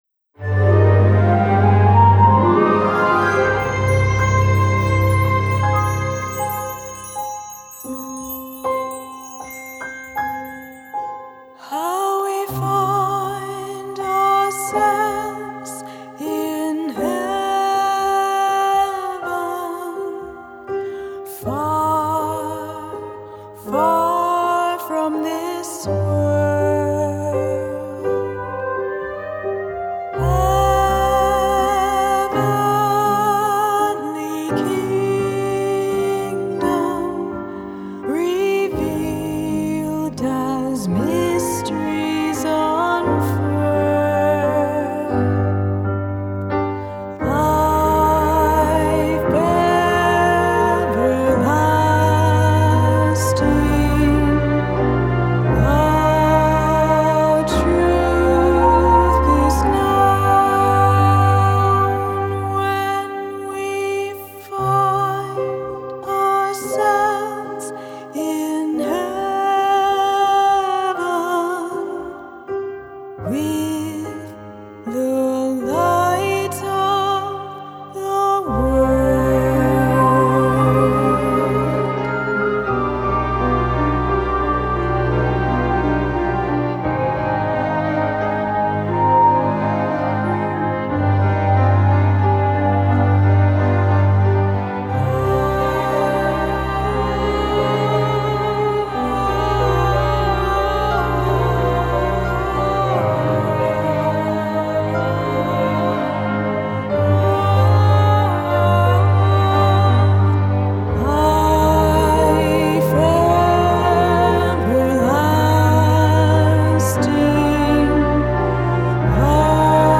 A Gothic Drama
Solo violin and viola